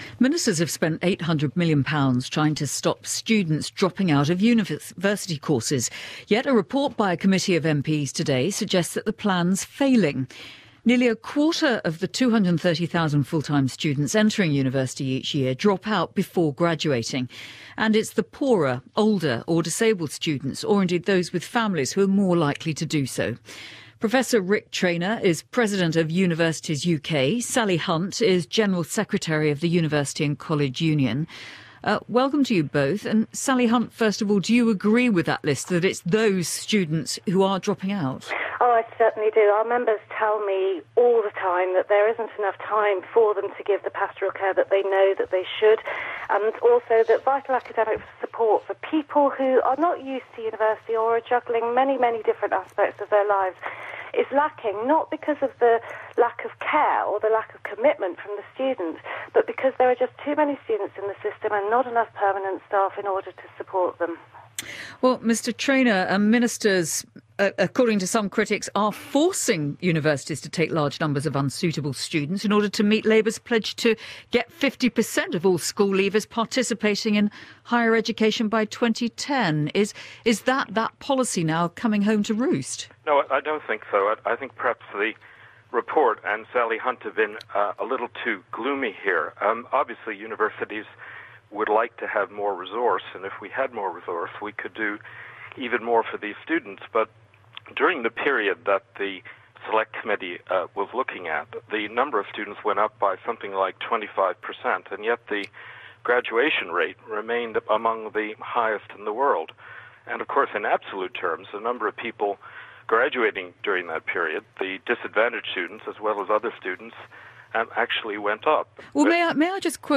BBC Radio 4 Today programme: interview on student drop-out rates